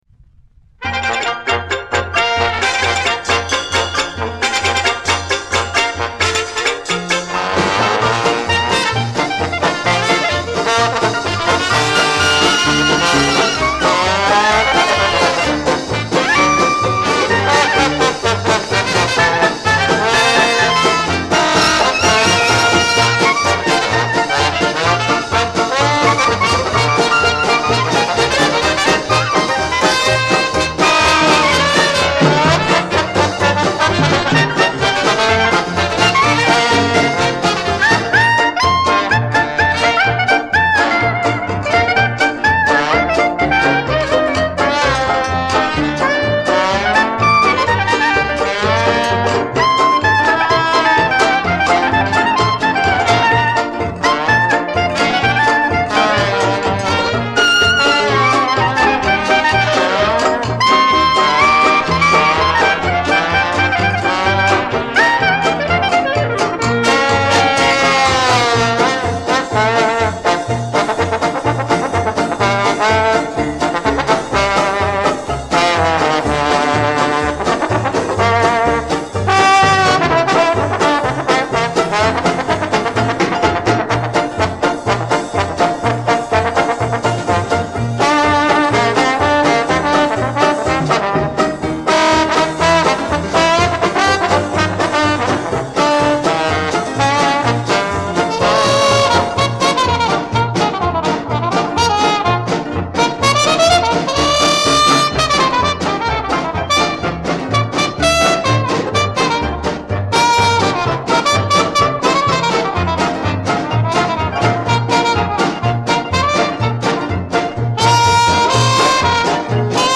A mysterious french band doing New Orleans style jazz?
Dixieland